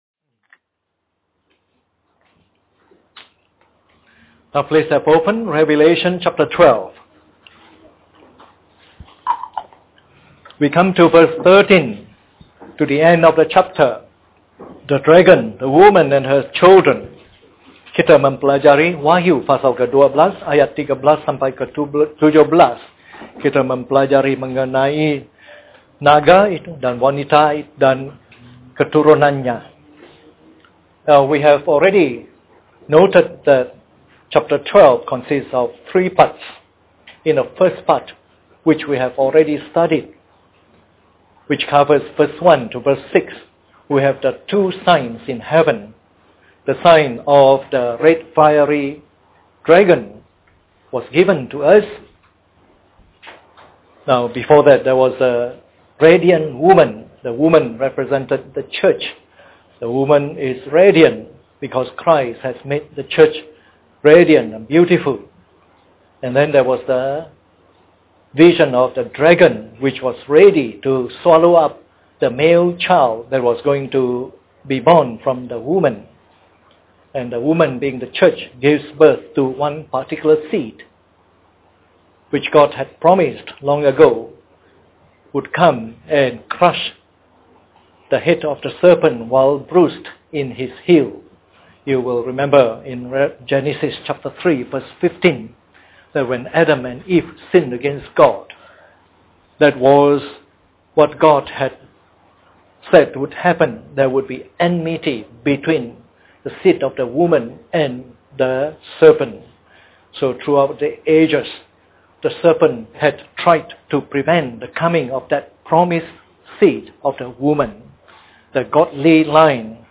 This is part of the morning service series on “Revelation”.